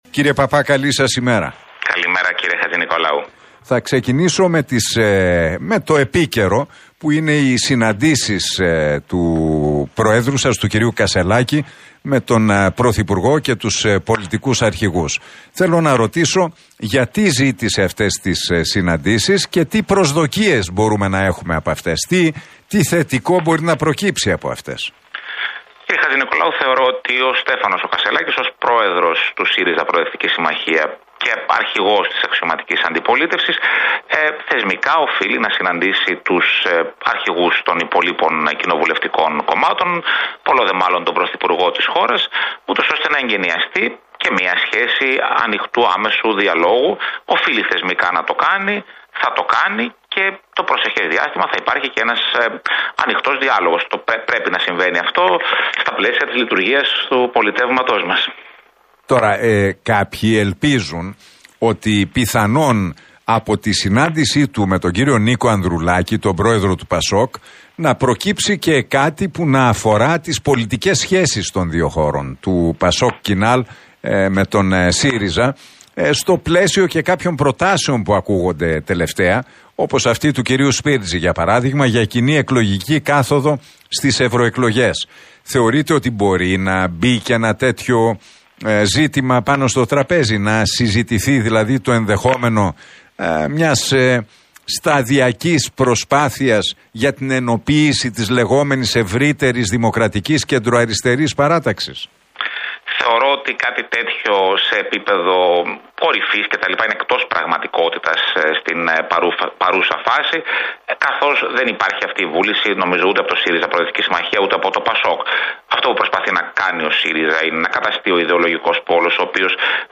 Παππάς στον Realfm 97,8: Περιθώριο συνέργειας μεταξύ του ΠΑΣΟΚ και του ΣΥΡΙΖΑ